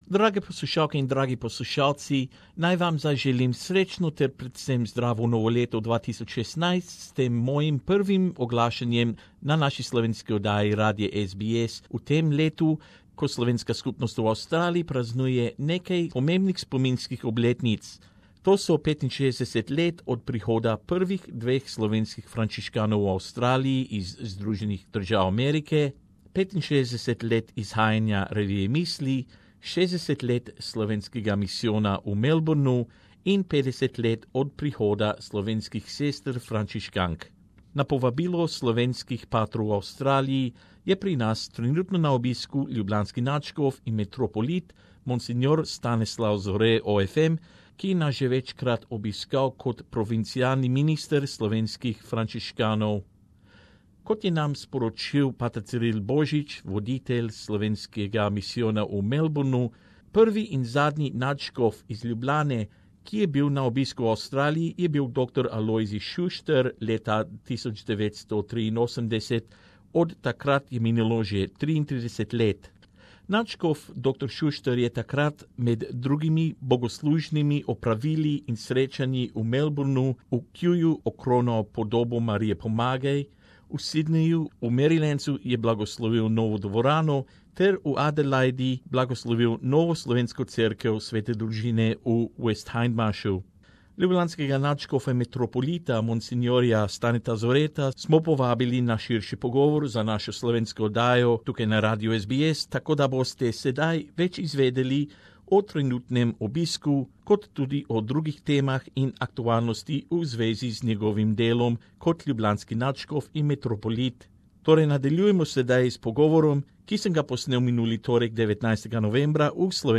We spoke to the current Archbishop of Ljubljana, Stane Zore about his visit Down Under and the valuable work which he undertakes in his senior role within the Catholic Church in Slovenia.